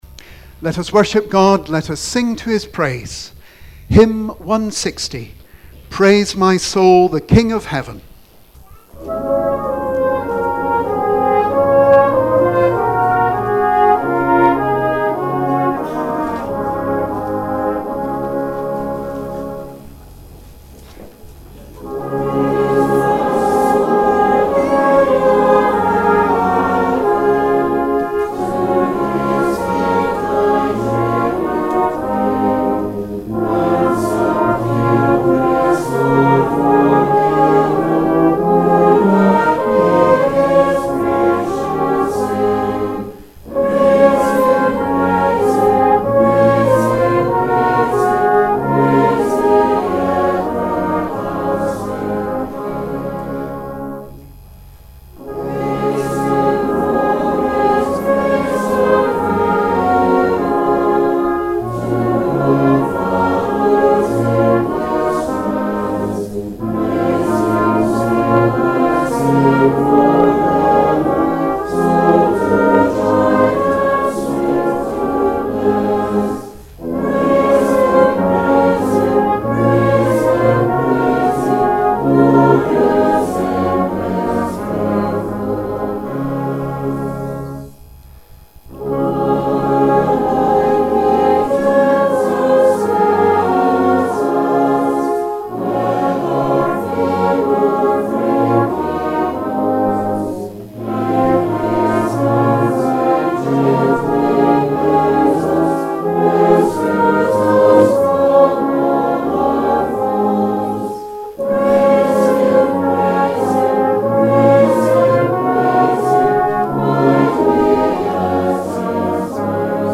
with Penicuik Silver Band leading the music for the praise.
This was followed by our traditional Remembrance opening hymn